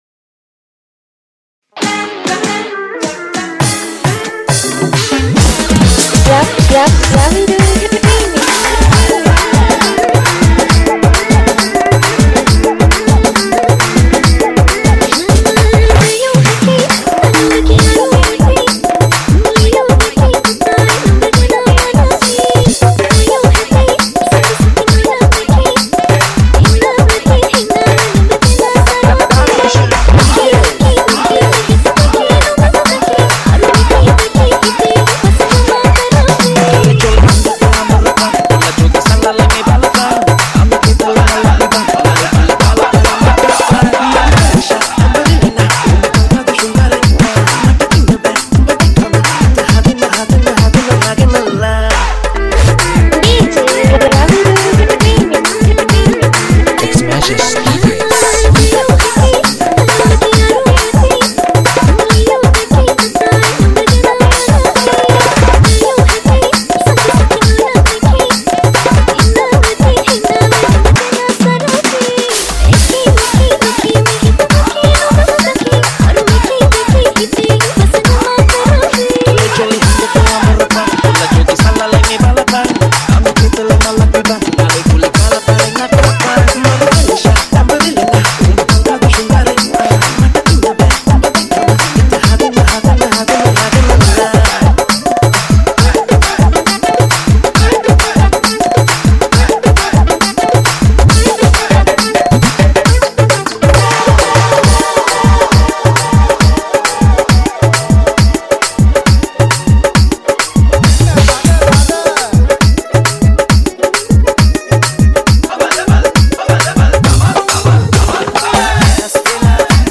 Sinhala Songs Hit Dj Nonstop